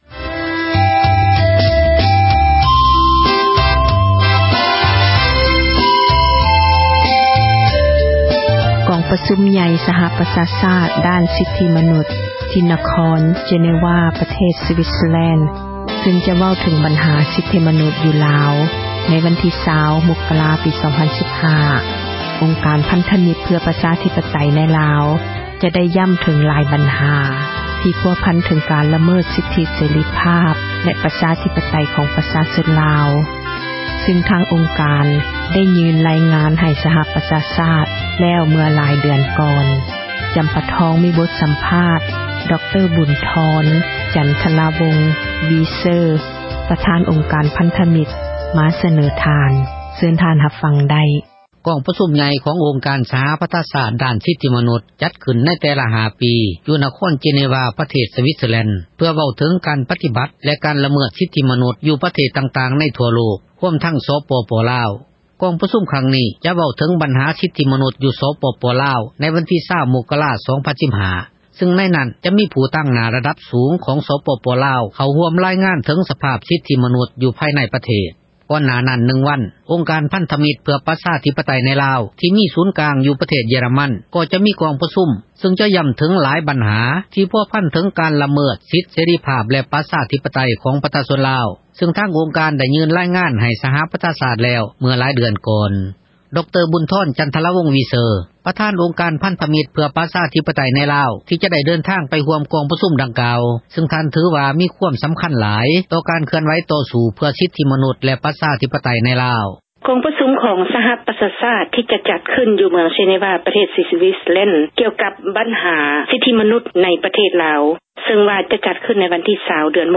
ບົດ ສໍາພາດ